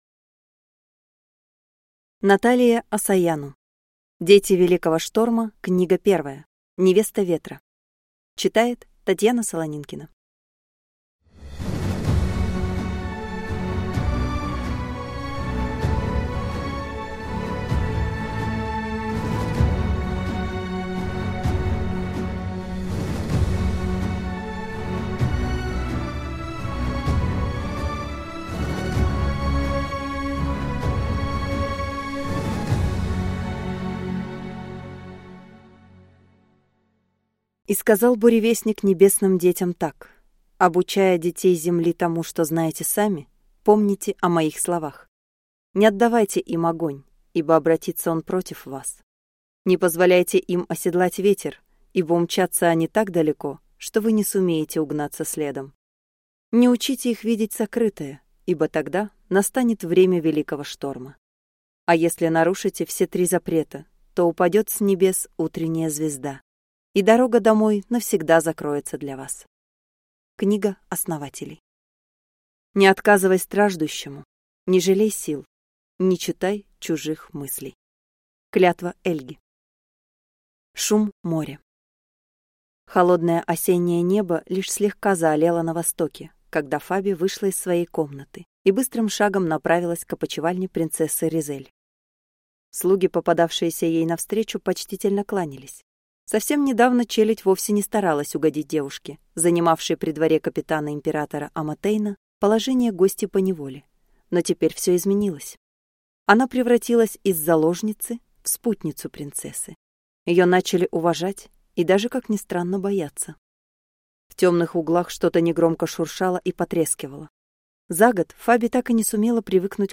Аудиокнига Невеста ветра | Библиотека аудиокниг